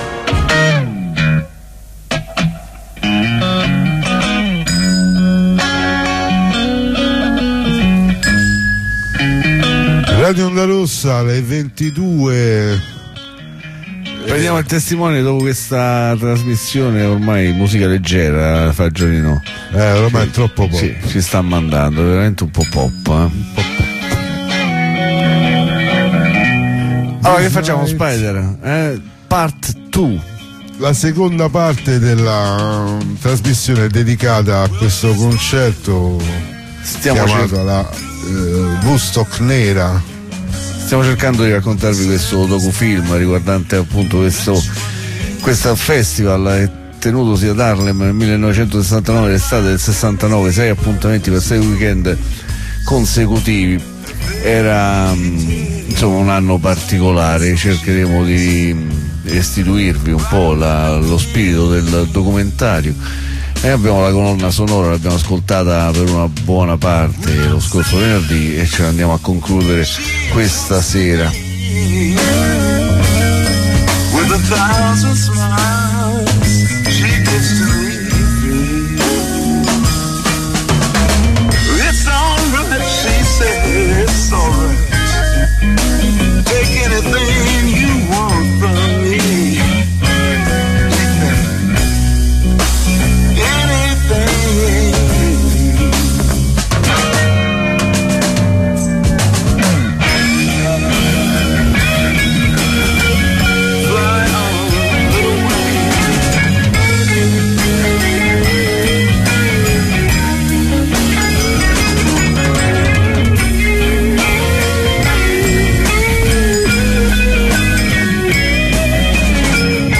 jazz blues soul funky blackmusic | Radio Onda Rossa